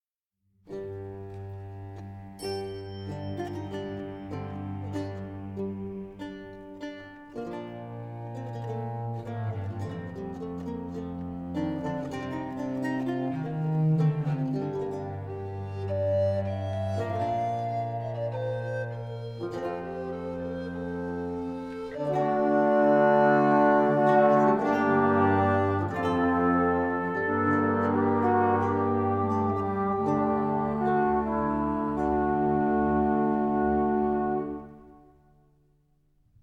Musique de scène
la musique originale pour chœur et orchestre de chambre